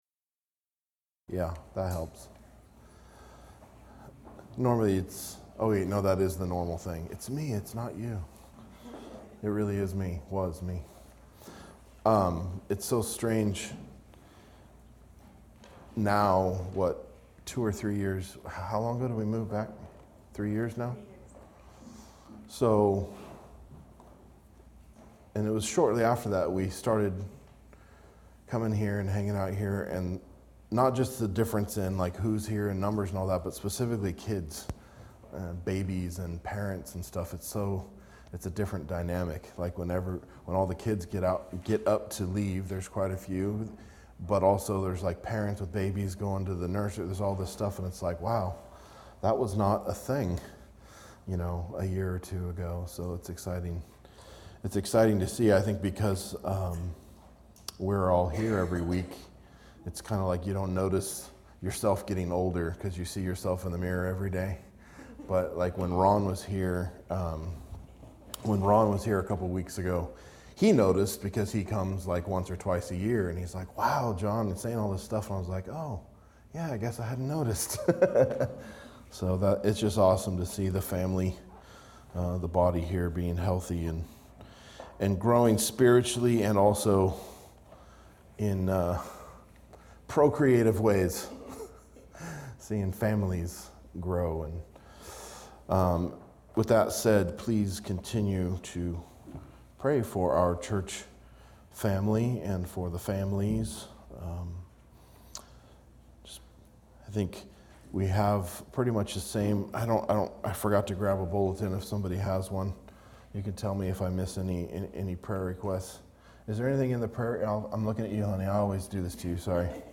Verse by verse exposition of Matthew's Gospel